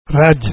ME' CA 'S PRUNÜNCIA LA LENGUA 'D VARSEI